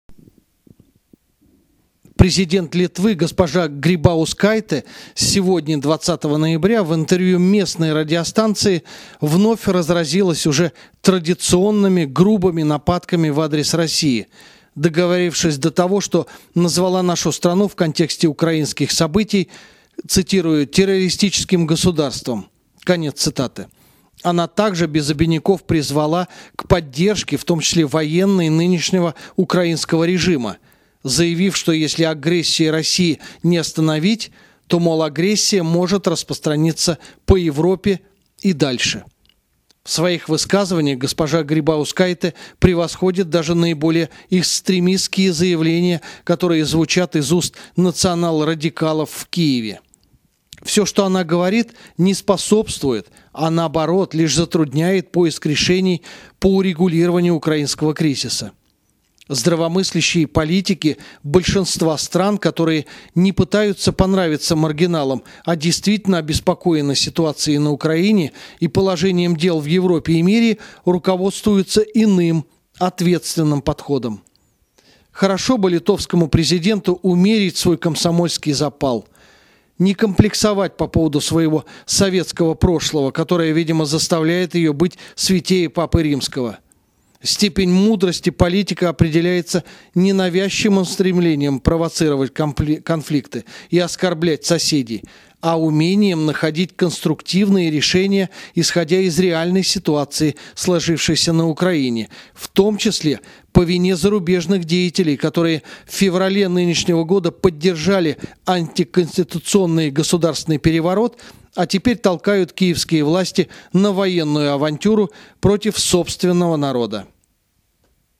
Комментарий официального представителя МИД России А.К.Лукашевича в связи с провокационными высказываниями Президента Литвы